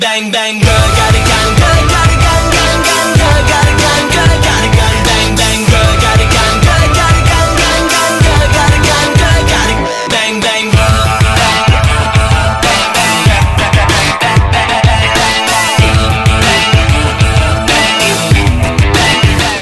• Качество: 192, Stereo
Драйвовые
веселые
Pop Rock
бодрые